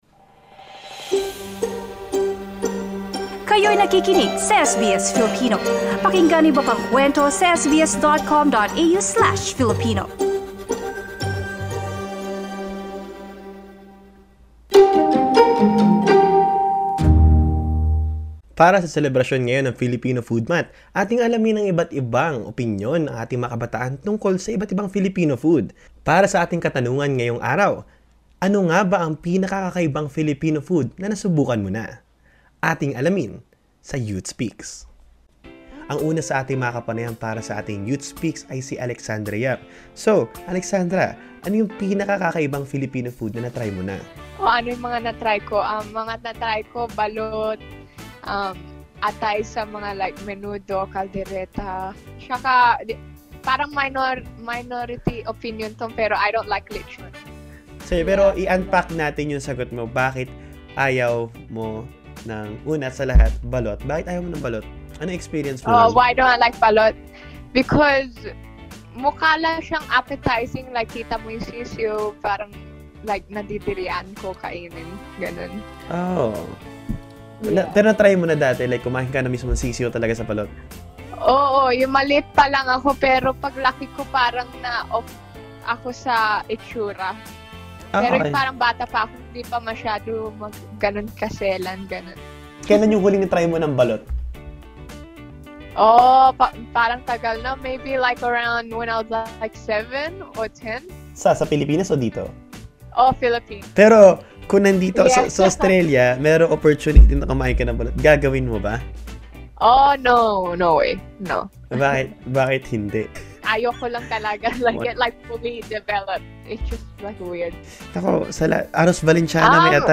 We have interviewed four young individuals to share their experiences and their thoughts what Filipino food do they find unusual.